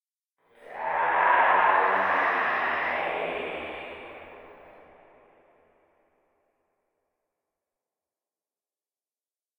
spawners_mobs_mummy_shoot.ogg